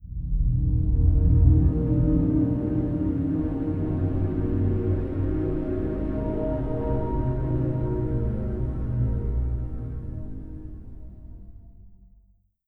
Samsung Galaxy S100 Startup.wav